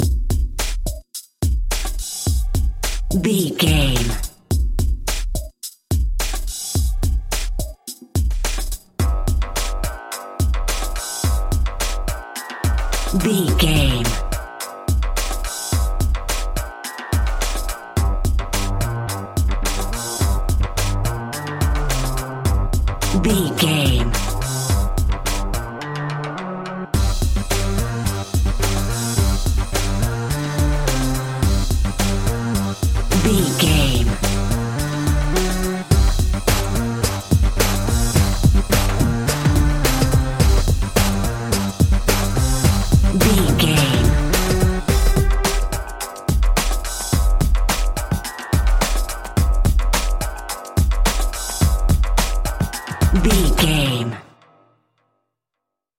In-crescendo
Thriller
Aeolian/Minor
tension
ominous
dark
eerie
Drum and bass
break beat
electronic
sub bass
synth drums
synth leads
synth bass